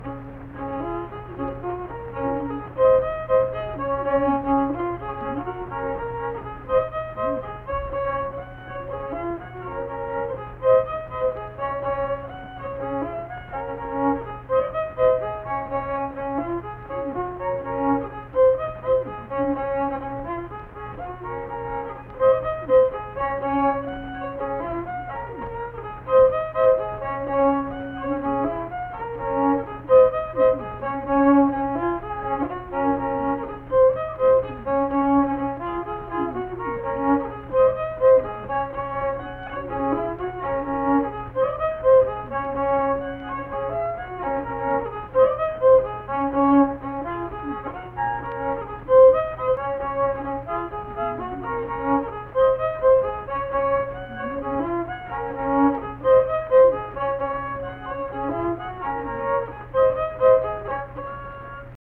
Unaccompanied fiddle music performance
Instrumental Music
Fiddle